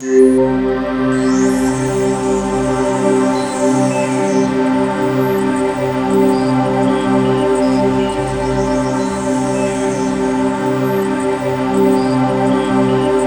Index of /90_sSampleCDs/USB Soundscan vol.13 - Ethereal Atmosphere [AKAI] 1CD/Partition A/08-SEQ PAD A
SEQ PAD03.-L.wav